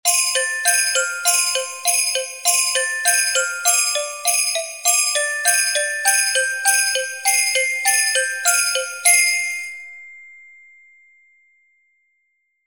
Jingle_Bells_Andante_100.mp3